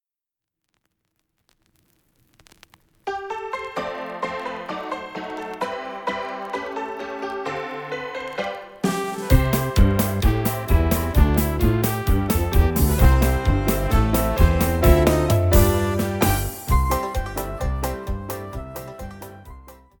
Choral Folk Light Concert/Novelty